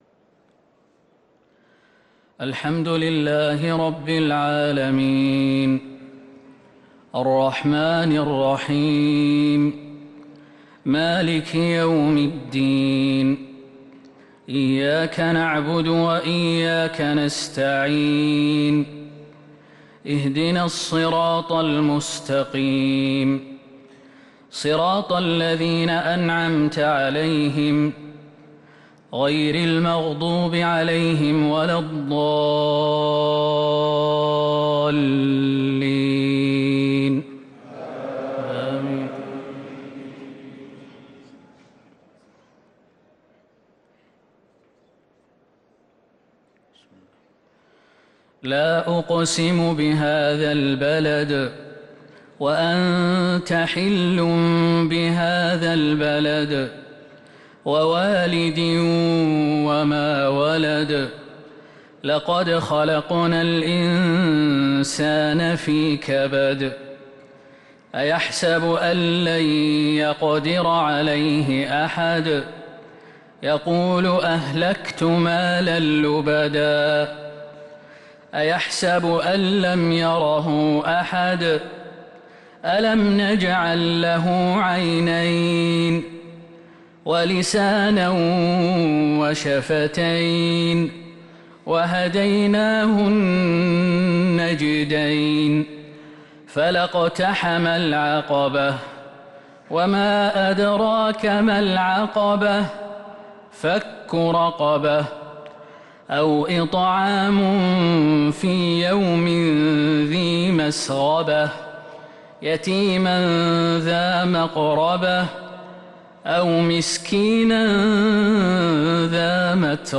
عشاء الأحد 8-6-1444هـ سورتي البلد و الشمس | Isha prayer from Surat al-Balad and Ash-Shams 1-1-2023 > 1444 🕌 > الفروض - تلاوات الحرمين